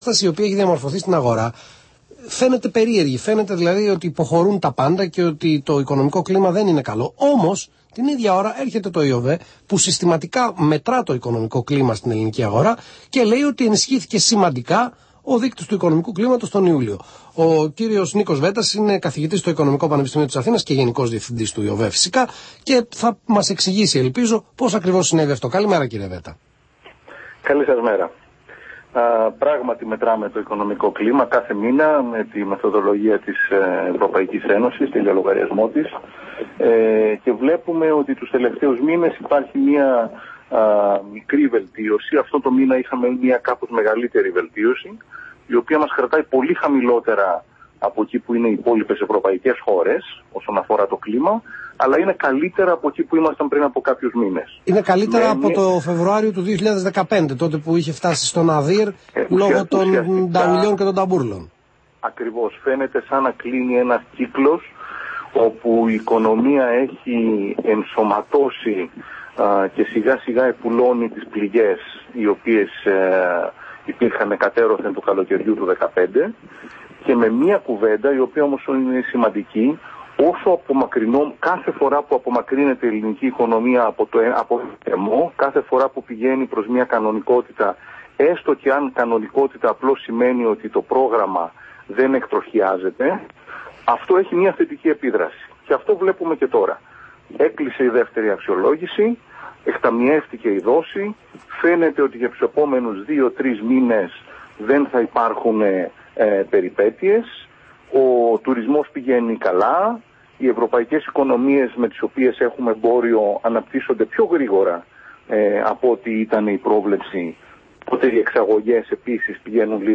Συνέντευξη στον Αθήνα 9,84